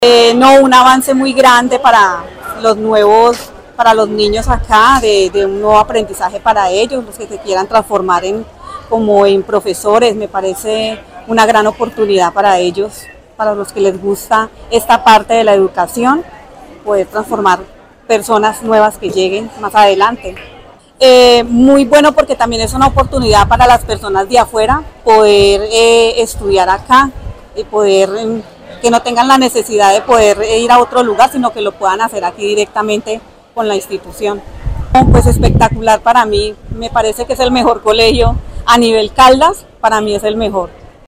madre de familia